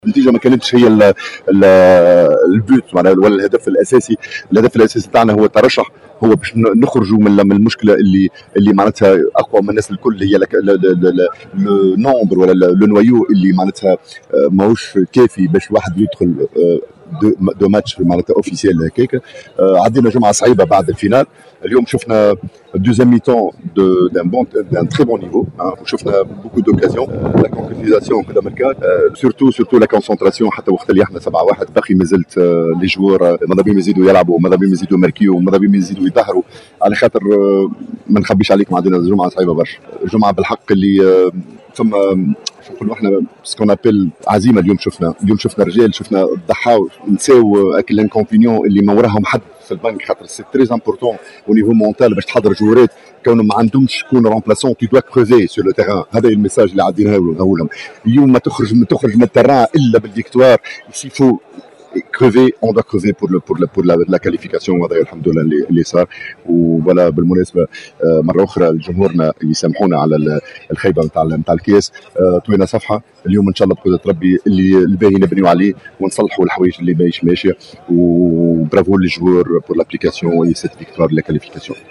تصريح لراديو مساكن